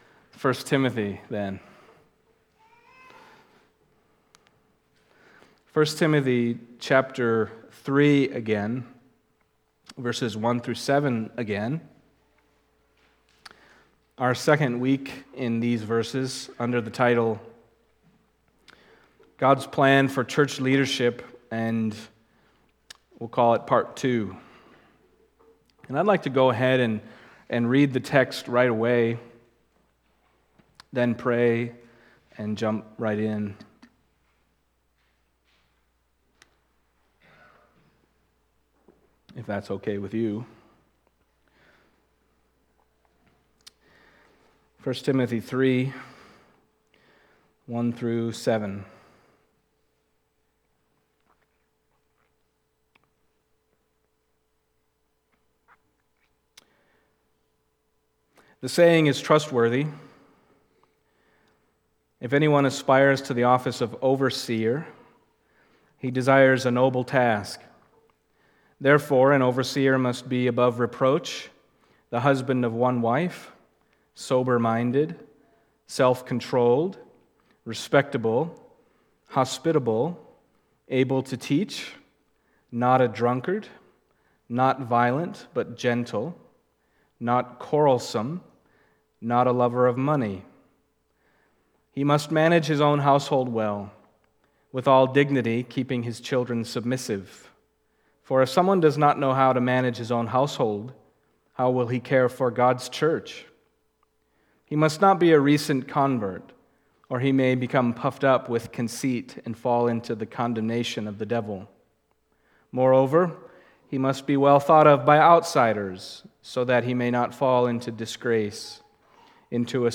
Passage: 1 Timothy 3:1-7 Service Type: Sunday Morning